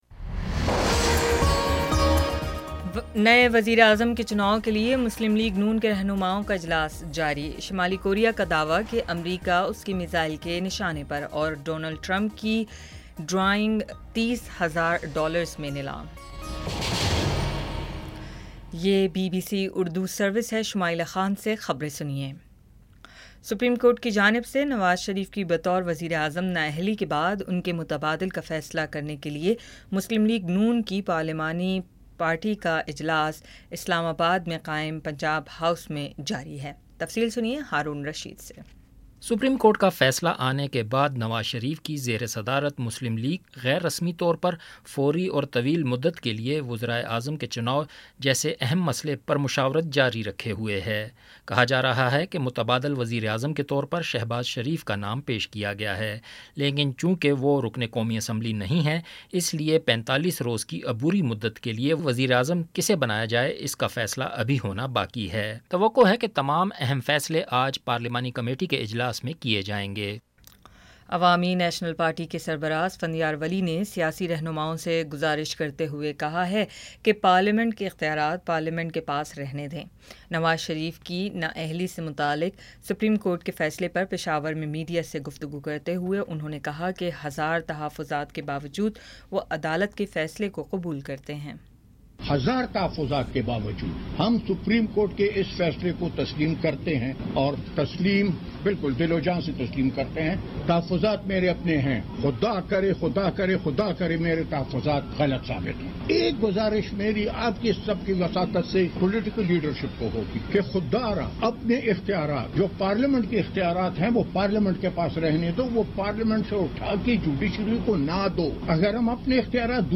جولائی 29 : شام چھ بجے کا نیوز بُلیٹن